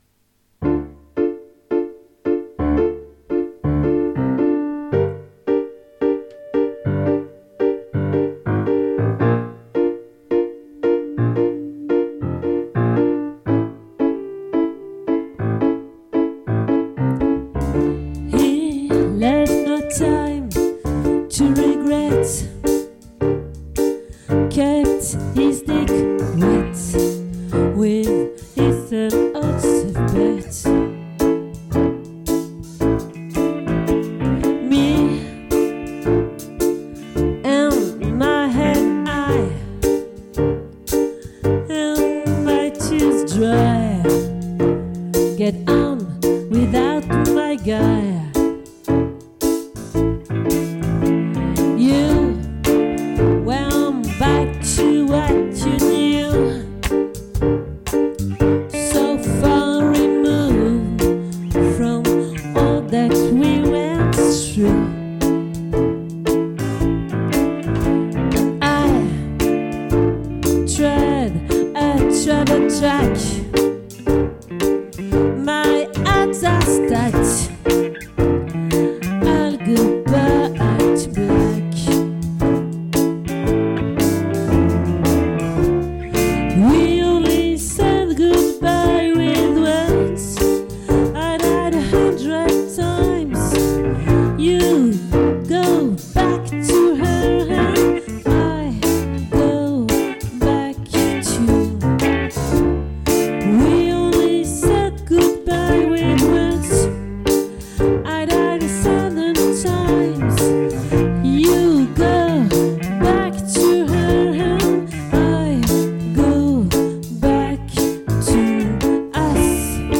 🏠 Accueil Repetitions Records_2023_01_04_OLVRE